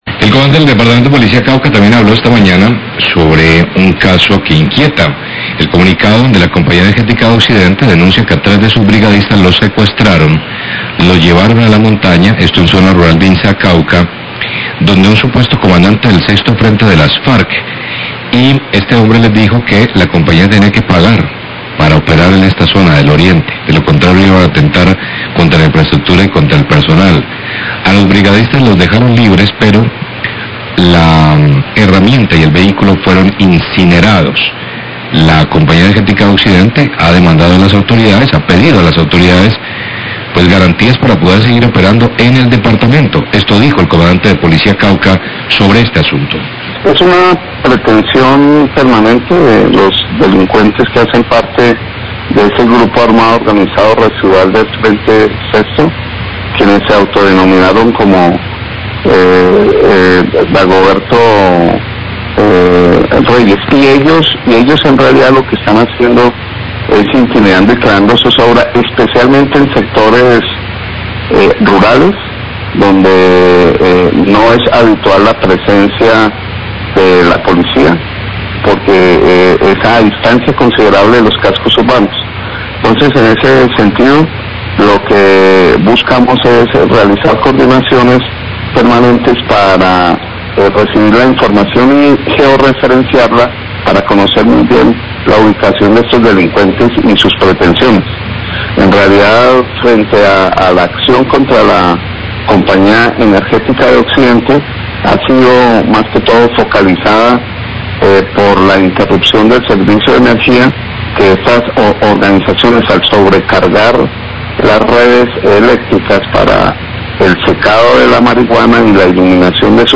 Radio
La Compañía Energética pide a las autoridades garantías de seguridad para seguir operando. Declaraciones del Comandante de la Policía Cauca.